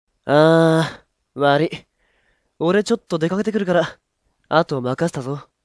１８歳／男
■　Voice　■